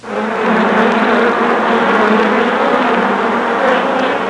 Bee Swarm Sound Effect
Download a high-quality bee swarm sound effect.
bee-swarm.mp3